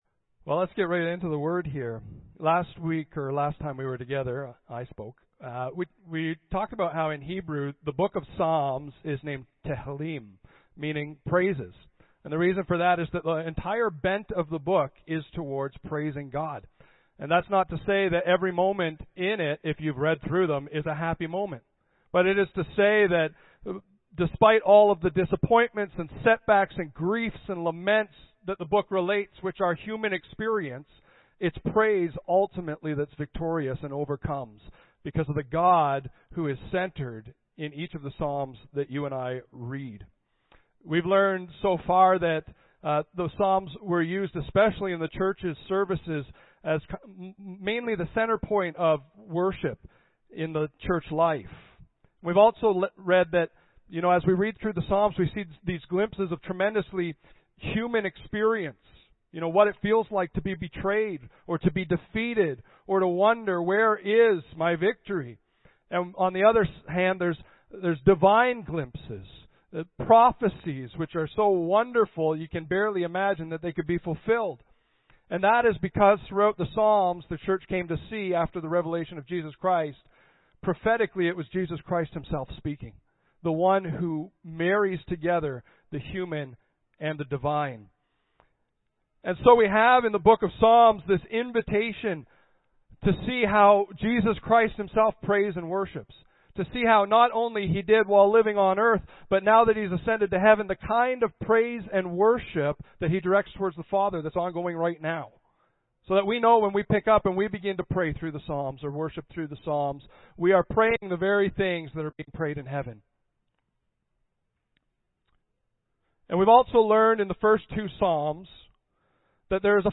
Audio Sermon Library Praise of Power.